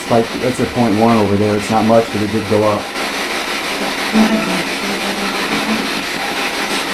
Spirit Box Clip 3
About the clip: This is another voice that came through the P-SB11 spirit box during the session in the "bad" area of the basement. During the entire spirit box session here, I also had a Mel-Meter (Electromagnetic Field, or EMF, detection device) set up on the floor about 3 feet to my right, I noticed a slight EMF spike on the Mel-Meter, and you can hear me document that in the clip. (Spikes in EMF readings can sometimes be an indicator of paranormal activity) As soon I finish talking, a clear female voice comes through, speaking two words, then pausing, then speaking one more word. The tone of the voice has a deep, "ethereal" quality that makes it sound as if it was in the room with us.